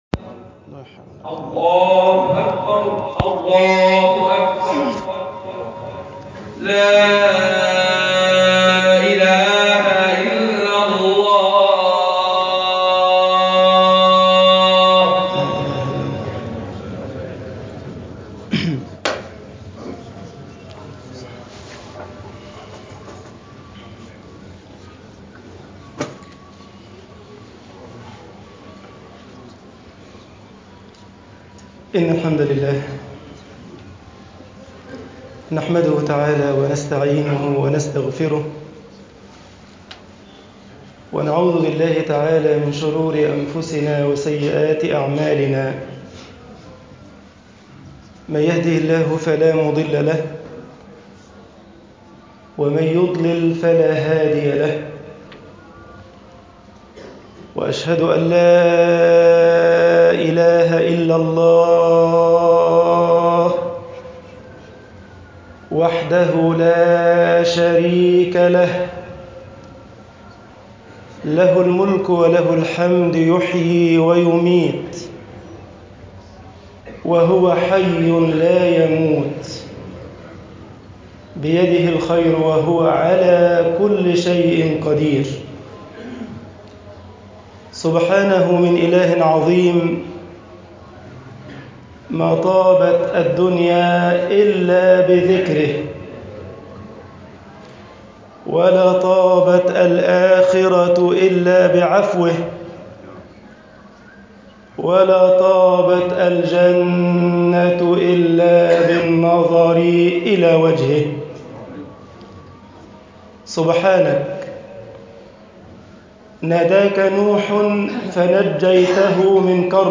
خطب الجمعة - مصر